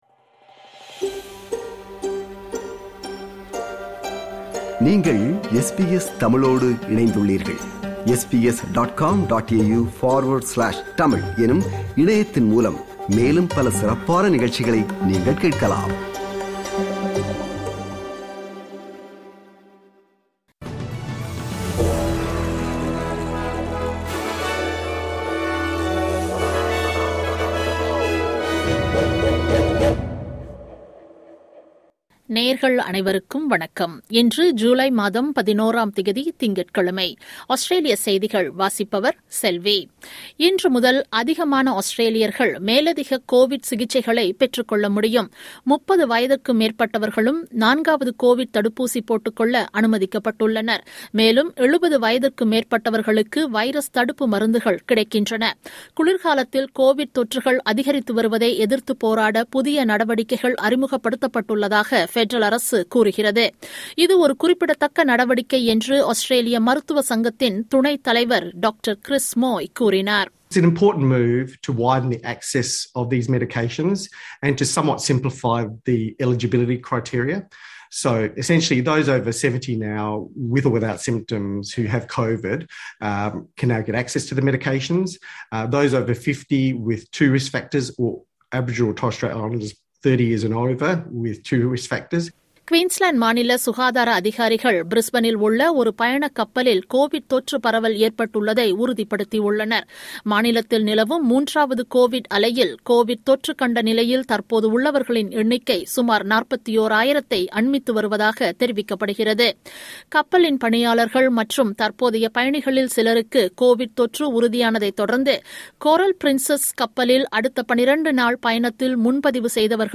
Australian news bulletin for Monday 11 July 2022.